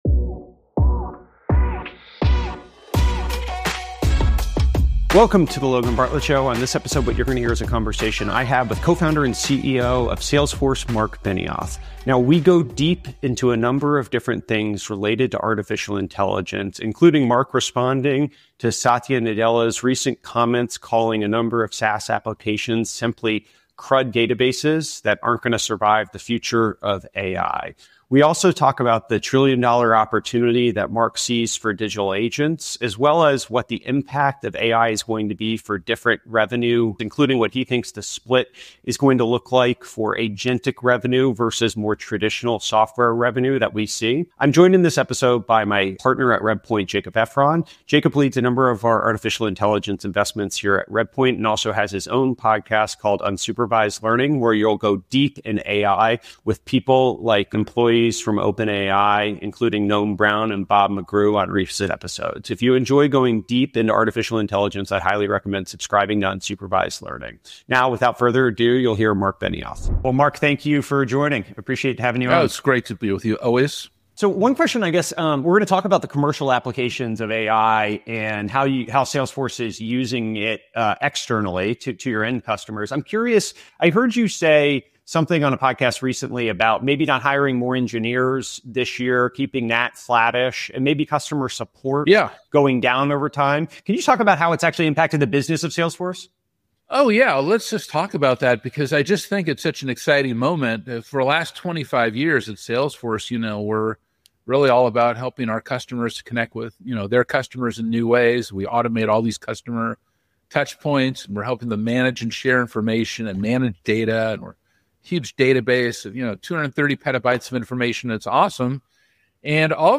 In this episode, Marc Benioff (CEO, Salesforce) responds to Satya Nadella’s recent predictions and shares his thoughts on the current reality of Agi. He dives into the rise of digital labor, the multi-trillion-dollar potential of agentic technology, and what the future split between software and agentic revenue might look like. Marc also discusses why CEOs need to stay grounded in delivering actionable solutions, and he emphasizes the moral obligation businesses have to retrain employees and invest in communities as AI continues to evolve.